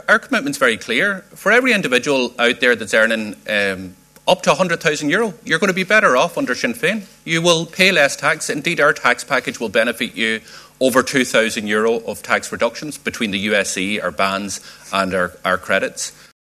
Finance Spokesperson and Donegal TD Pearse Doherty says average workers will be better off……………….